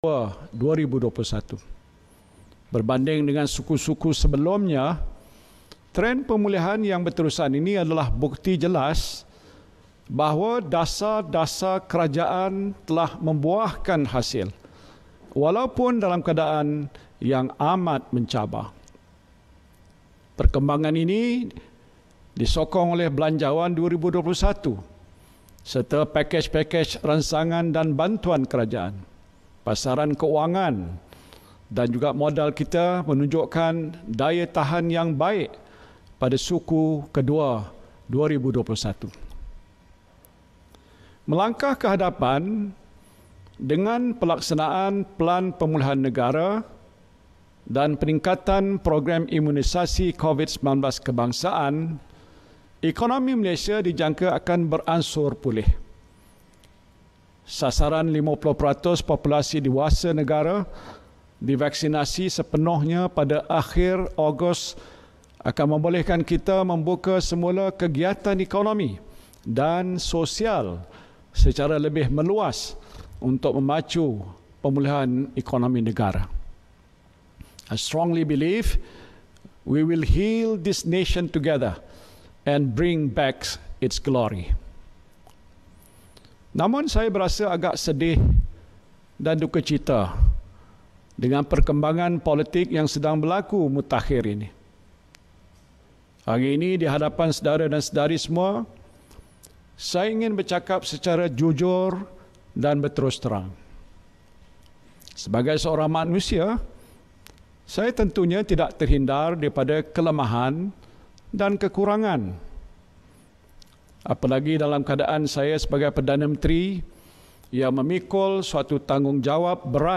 LIVE-Perutusan-khas-oleh-Perdana-Menteri Muhyiddin.mp3